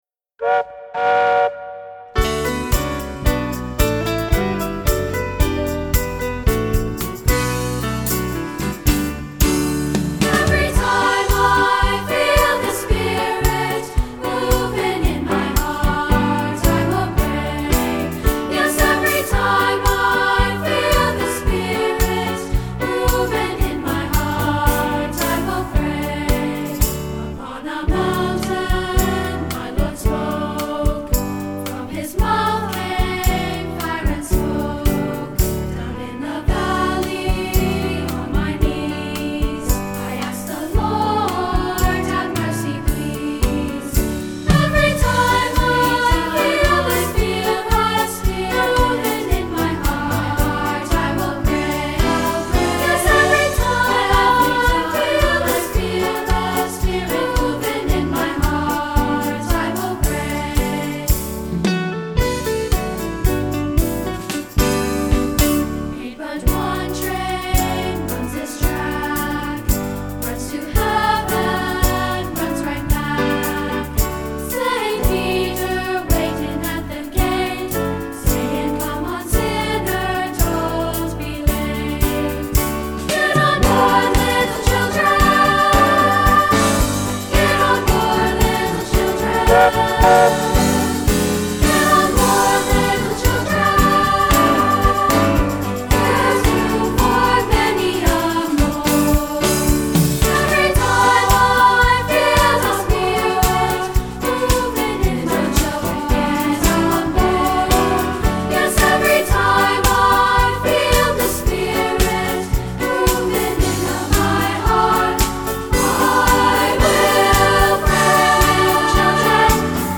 Voicing: SA and Piano